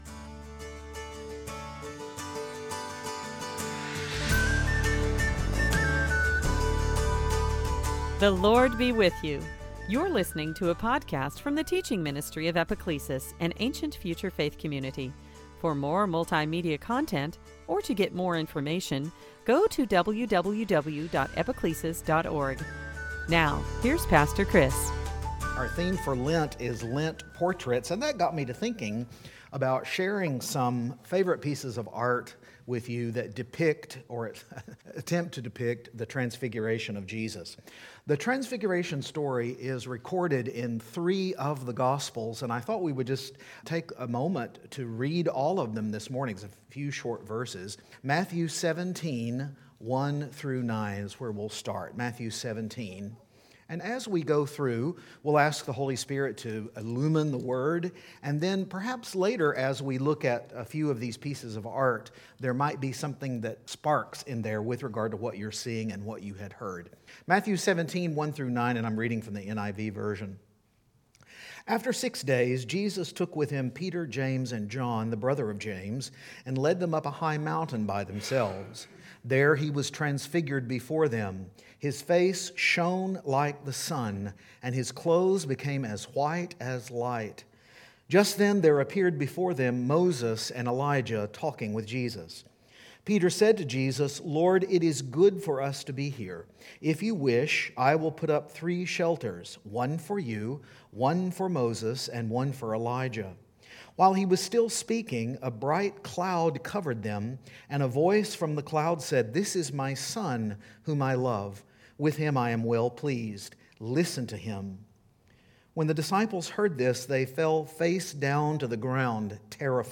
Service Type: Transfiguration Sunday